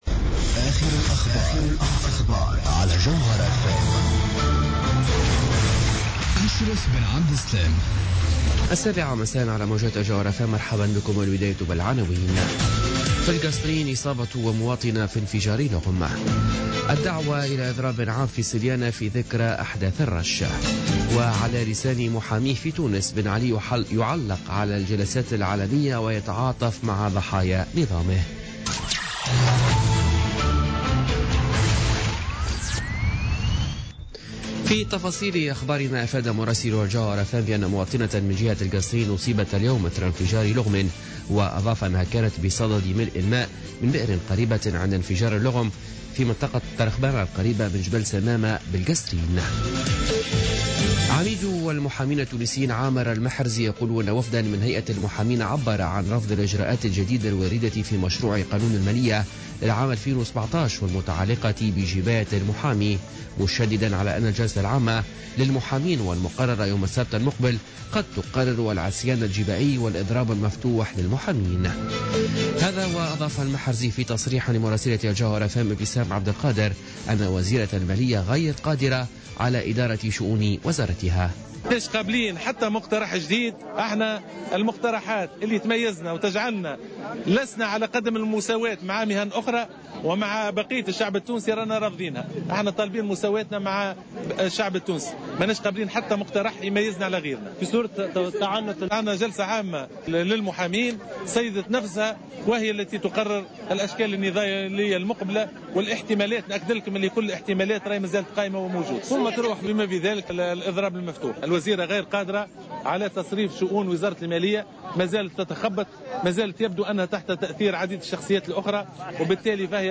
Journal Info 19h00 du mercredi 23 novembre 2016